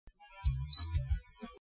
Message Alerts
I found the Power Rangers Watch Sound
Power Ranger Watch Sound